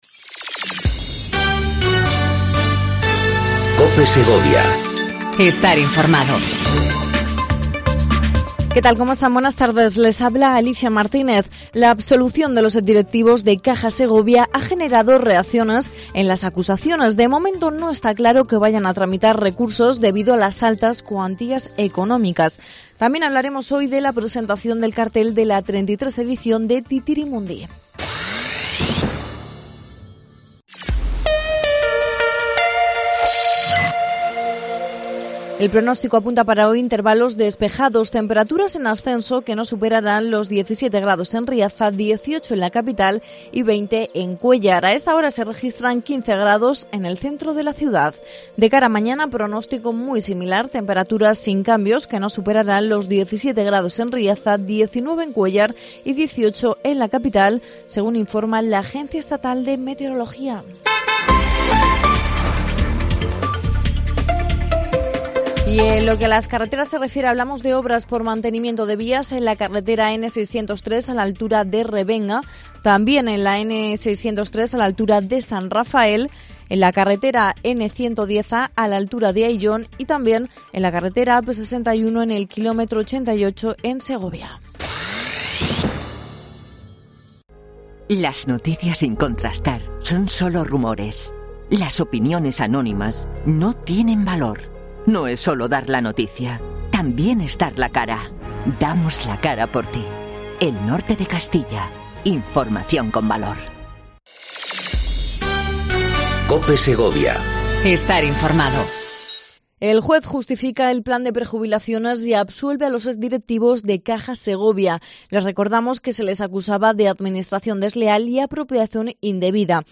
AUDIO: Repaso informativo a la actualidad local y provincial 27/03/19